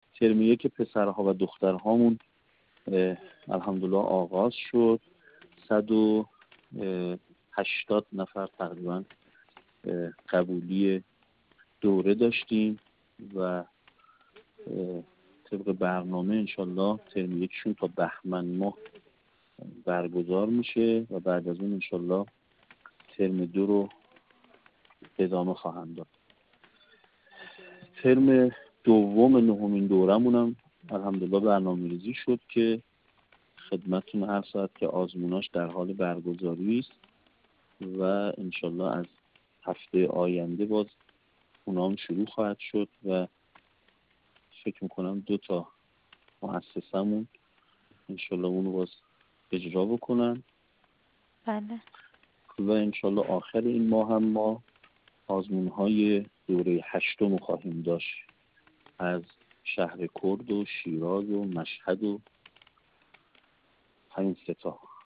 در گفت‌وگو با ایکنا